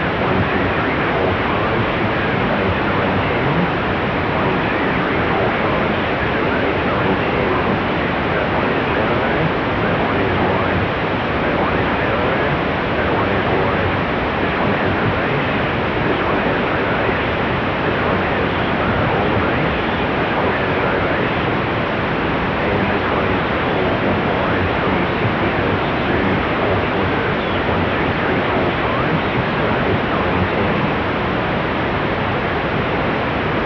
Then, white noise was mixed over the audio, with a -5 dB speech ratio for normal clipping, and a -6 dB speech ratio for maximum clipping (measured in a 5.5 kHz bandwidth).
Maximum clipping, Mode 5 + EQ boost @ -6 dB SNR - Utility modes stepped through to change bandwidth NARROW RECEIVE 250 Hz - 3 kHz